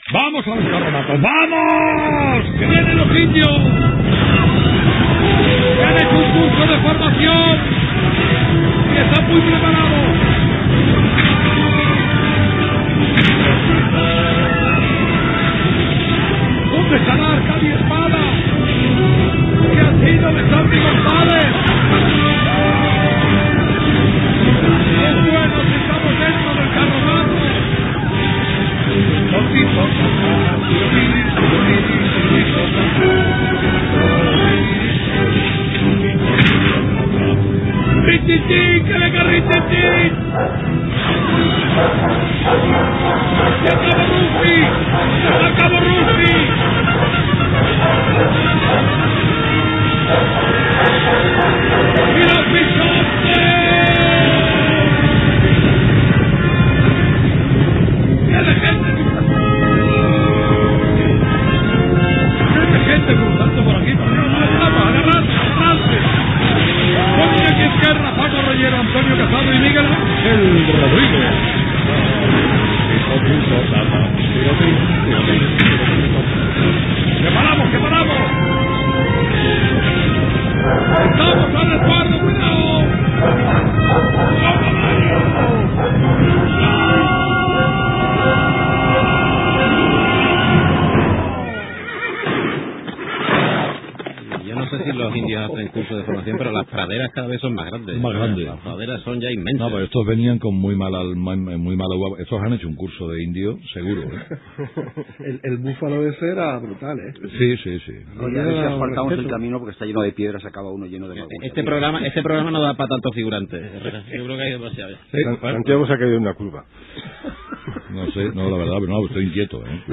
Carlos Herrera ficcionava una pel·lícula de l'oest per iniciar la tertúlia del programa.
Info-entreteniment